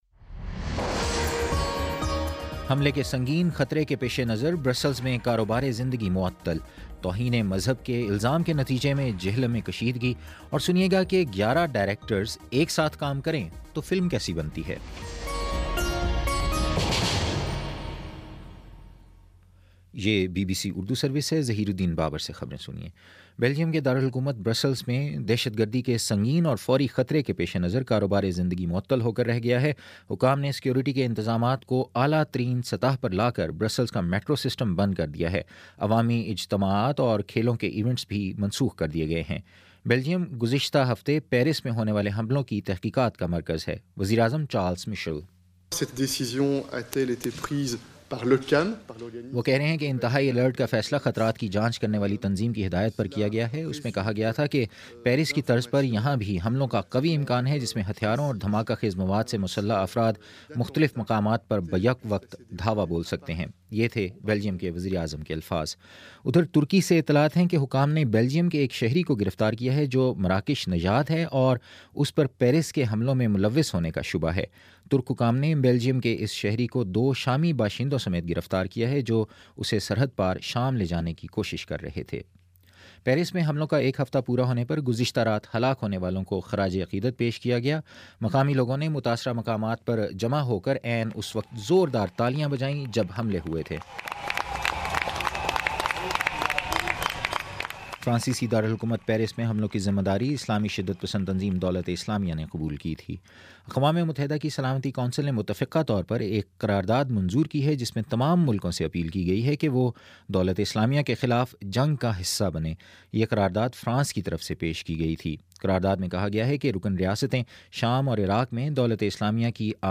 نومبر 21 : شام پانچ بجے کا نیوز بُلیٹن